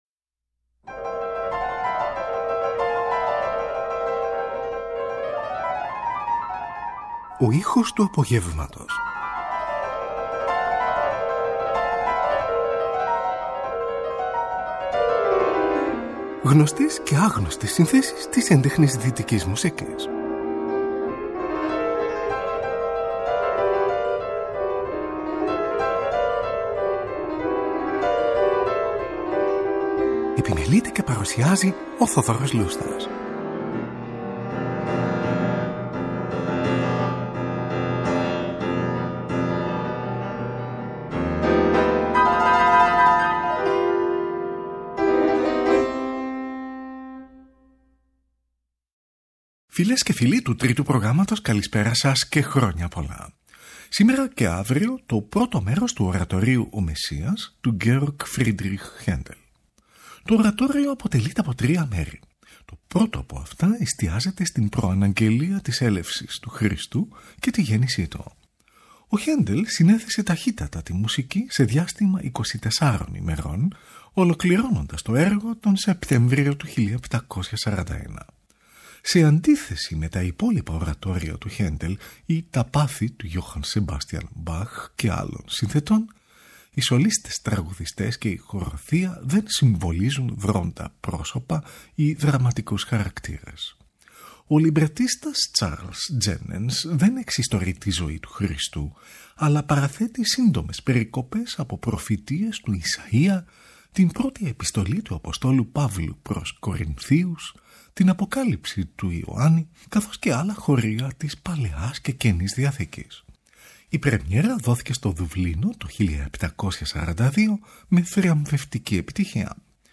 ορατορίου
Σολίστ είναι η Βρετανίδα υψίφωνος